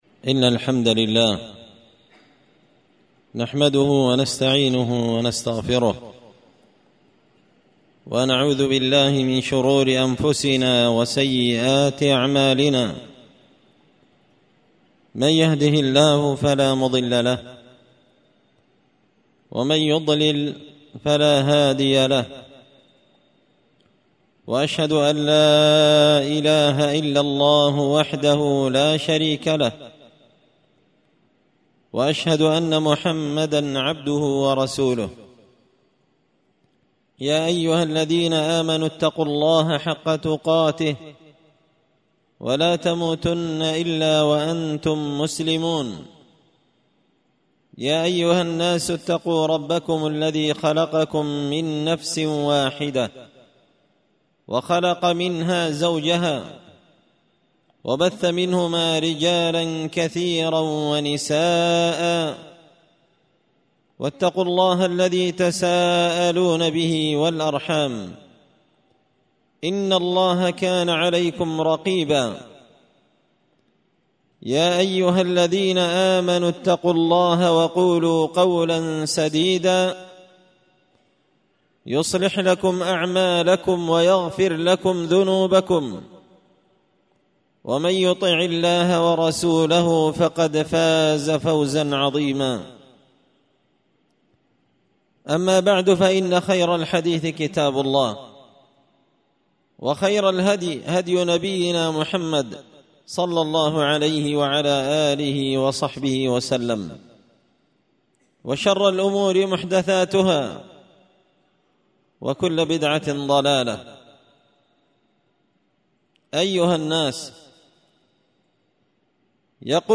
خطبة جمعة بعنوان:
ألقيت هذه الخطبة بمسجد معاذ بن جبل بفوة المساكن فوة-المكلا-اليمن
خطبة-جمعة-بعنوان-قوله-تعالى-فمن-يعمل-مثقال-ذرة-خيراً-يره-ومن-يعمل-مثقال-ذرة-شراً-يره.mp3